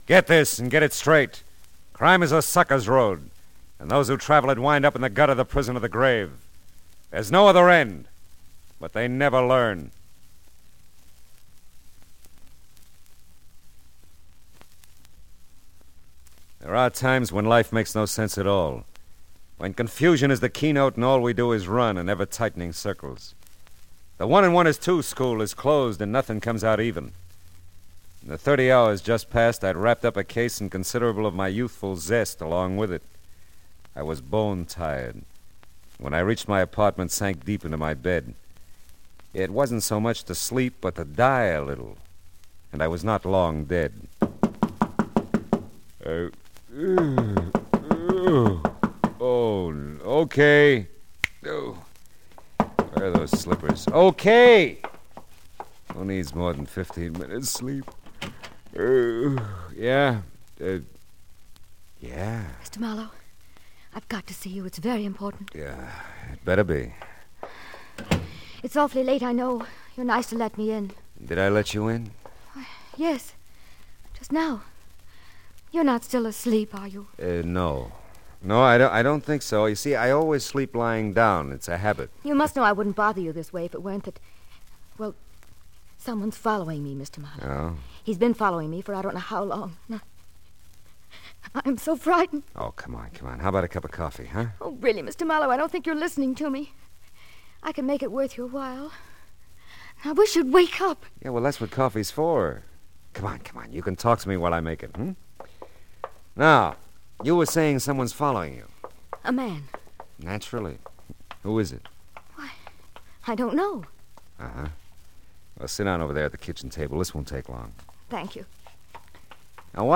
The Adventures of Philip Marlowe - Nether Nether Land (rehearsal)